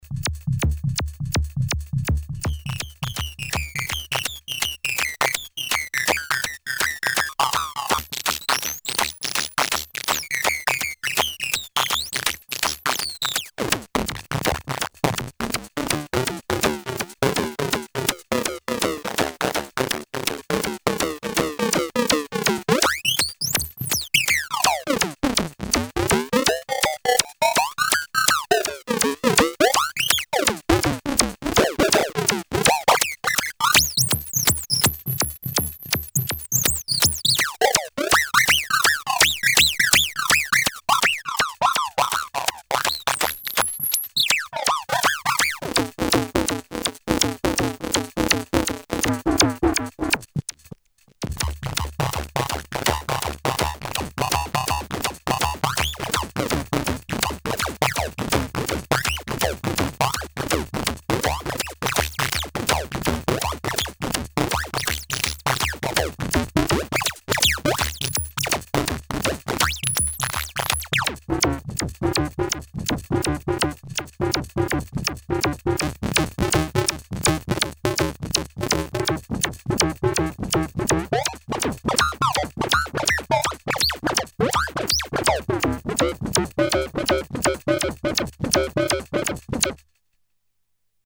A quite complete variable carrier ring modulator effect with modulation and envelope follower.
demo with Kawai K5000s